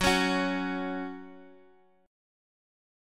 F#5 chord